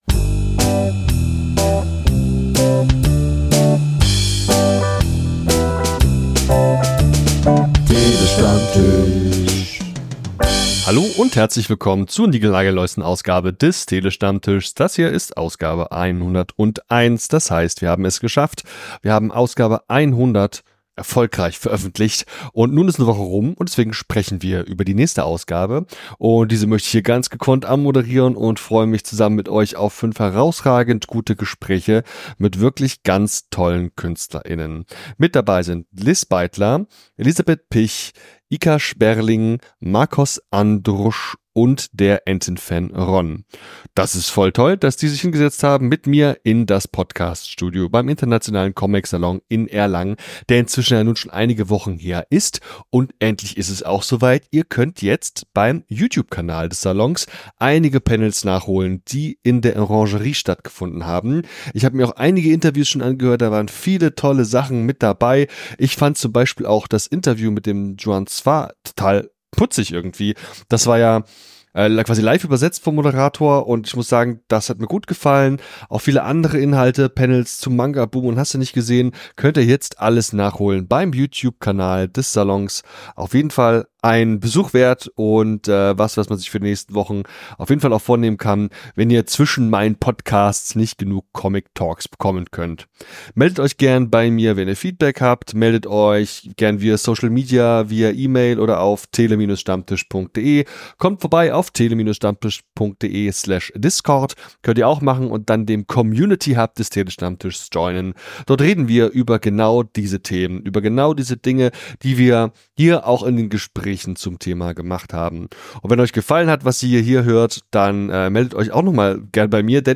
Ich habe vor Ort sehr viel gearbeitet und viele Stunden lang Interviews geführt und aufgezeichnet.